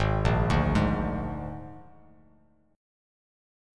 G#sus2b5 chord